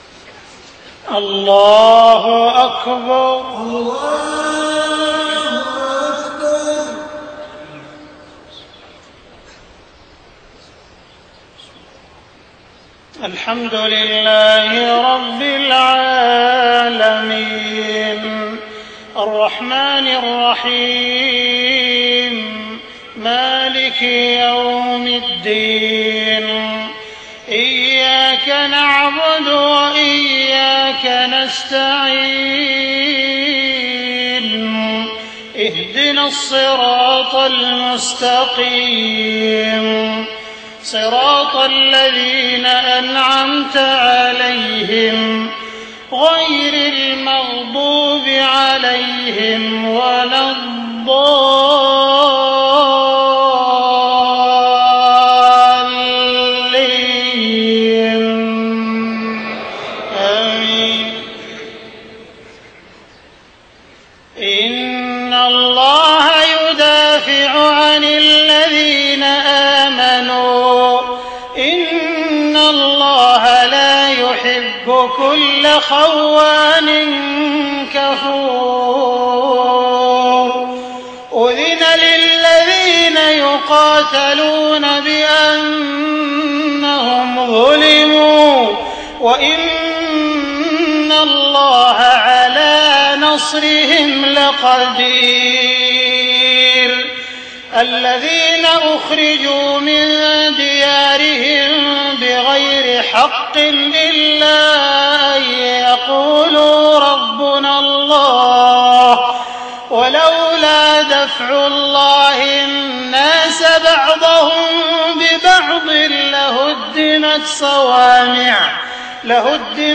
صلاة المغرب 4 محرم 1430هـ من سورة الحج 38-41 و الكوثر > 1430 🕋 > الفروض - تلاوات الحرمين